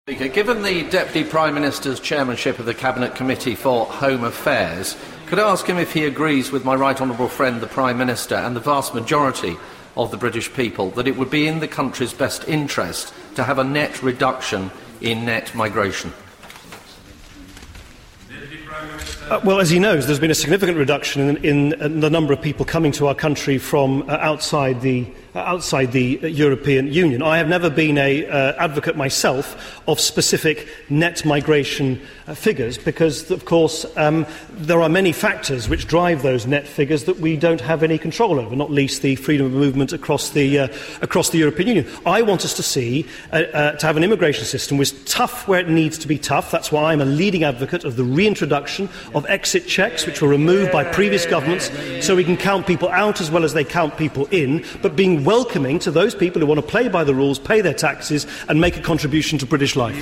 Questioned by Mel Stride at DPMQs. House of Commons, 14 May 2014